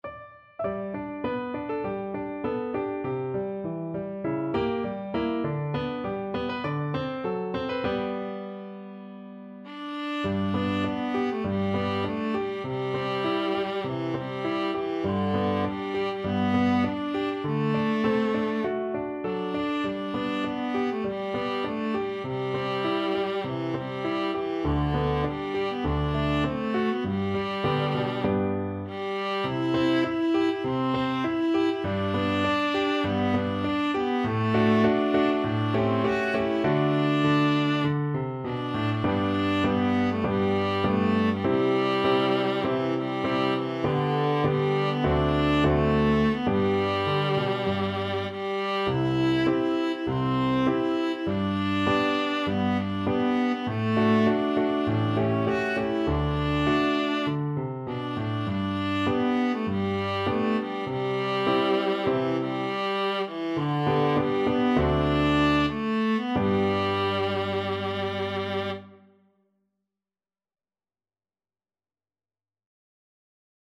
Viola
Traditional Music of unknown author.
4/4 (View more 4/4 Music)
~ = 100 Moderato
G major (Sounding Pitch) (View more G major Music for Viola )